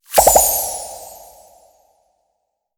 Звуки эмодзи